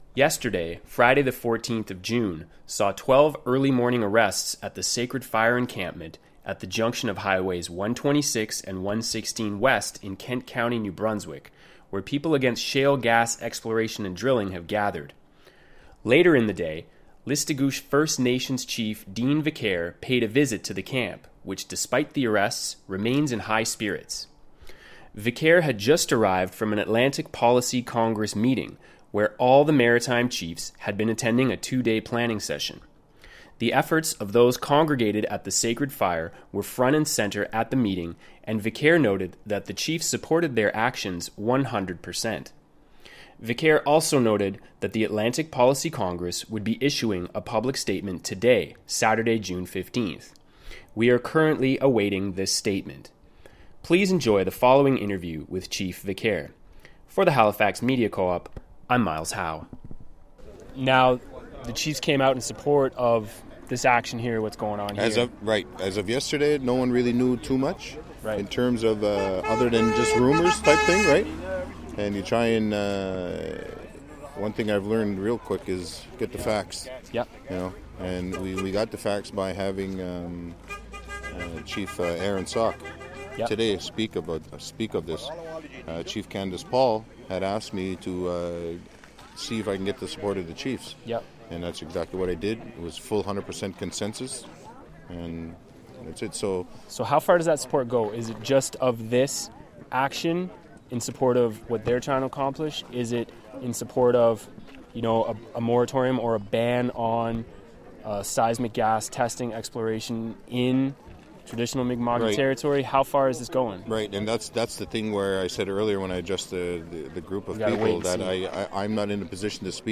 16-1-chiefvicaireinterview.mp3